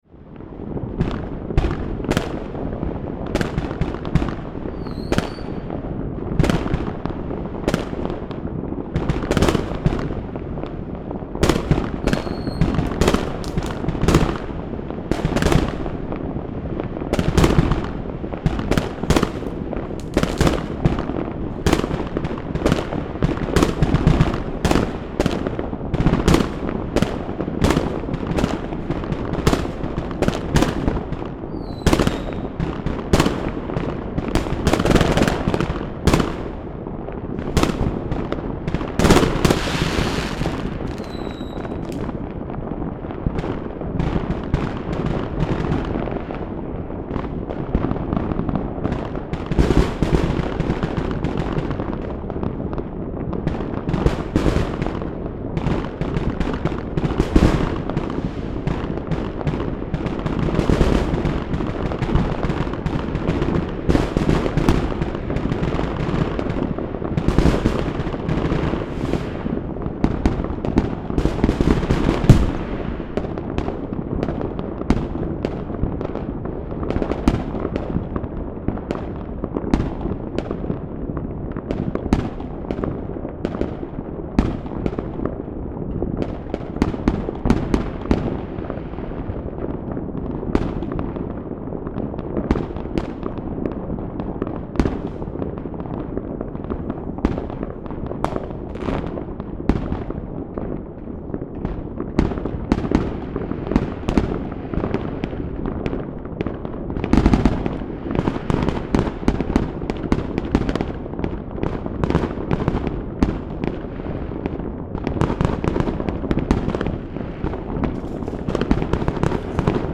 This recording was made in my backyard, between houses with LCT540s in IRT. You can hear that the echo time is short.
But the Limiter was turned off, as always should be done when recording fireworks. Recording sound pressure rose to a peak of +7.7dB (-7.4 LUFS-S) . Details that can often be heard in fireworks when they are launched are not heard clearly in this recording because they were launched from beyond the houses in this backyard.